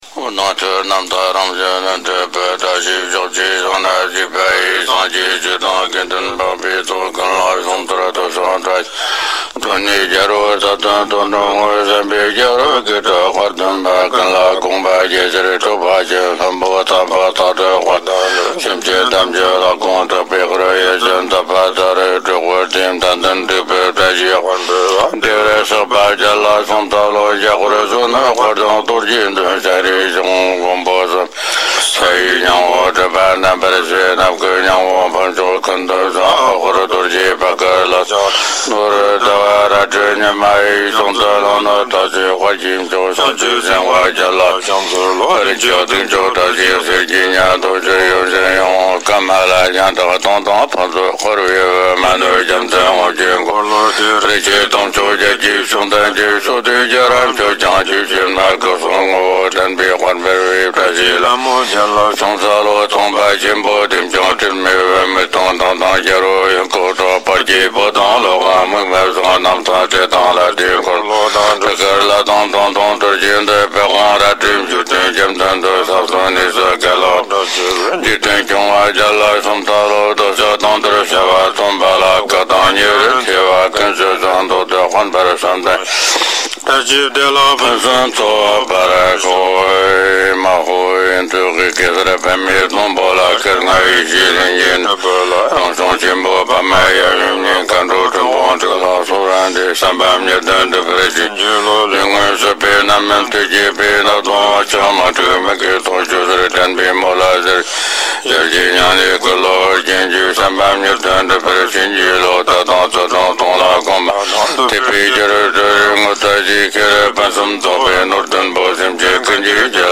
八吉祥--第四世多智钦 经忏 八吉祥--第四世多智钦 点我： 标签: 佛音 经忏 佛教音乐 返回列表 上一篇： 观音菩萨心咒--吉尊玛 下一篇： 普贤行愿品-七支供养偈--印度萨迦寺僧众 相关文章 千手千眼无碍大悲心陀罗尼--比丘尼僧团 千手千眼无碍大悲心陀罗尼--比丘尼僧团...